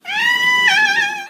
dave-the-cat-says-yeah-short_17123.mp3